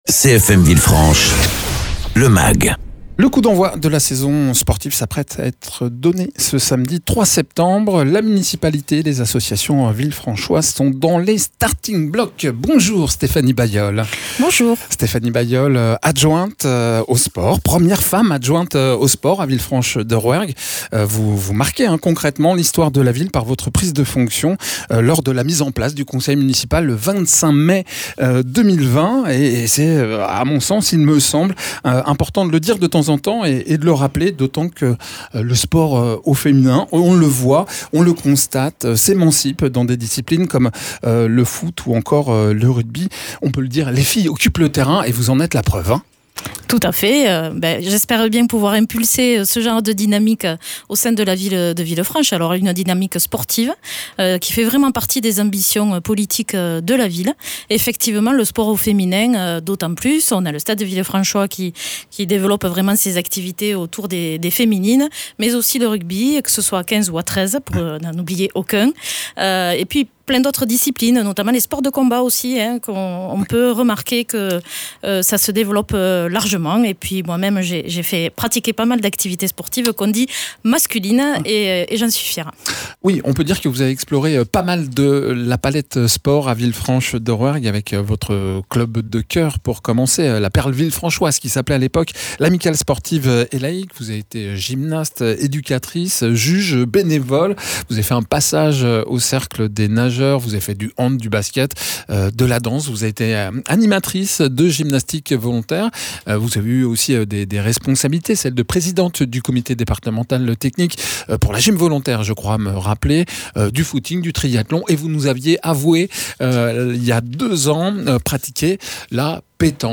Interviews
Invité(s) : Stéphanie Bayol, Adjointe aux sports, mairie de Villefranche